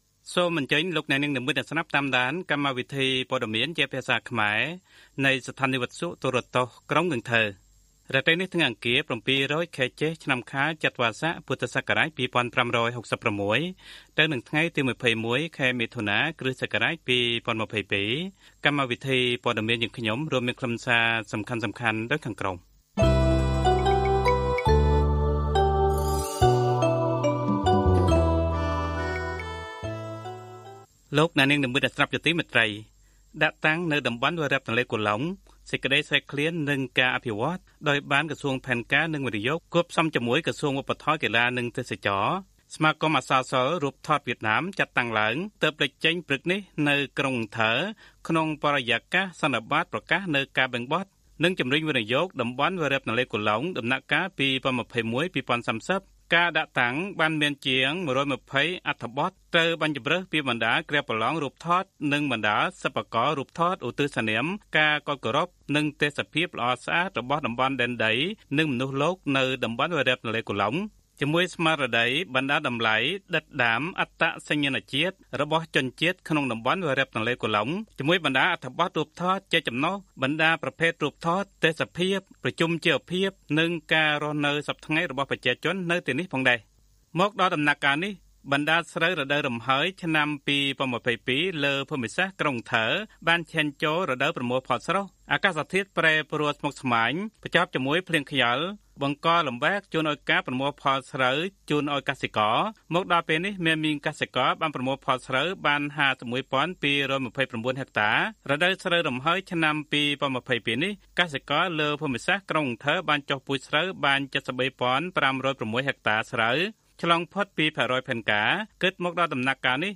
Bản tin tiếng Khmer tối 21/6/2022